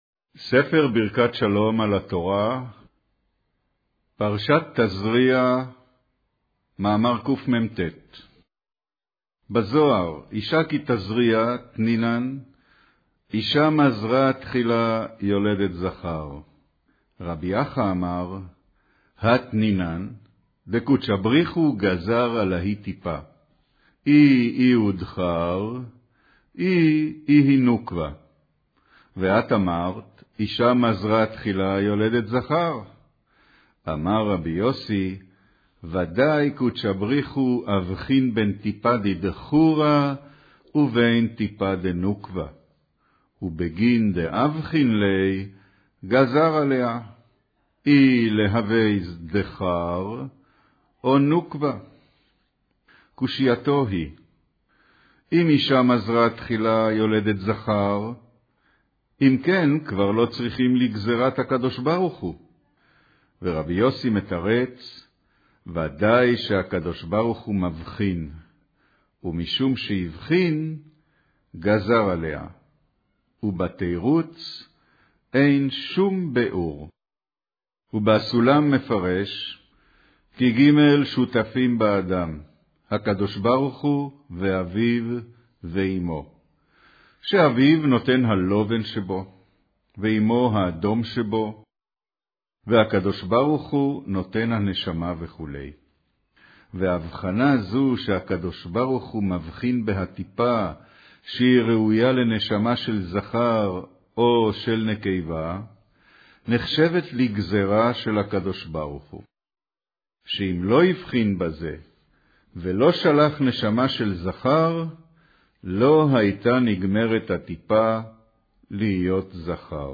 אודיו - קריינות פרשת תזריע, מאמר אישה כי תזריע וילדה זכר